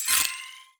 Special & Powerup (10).wav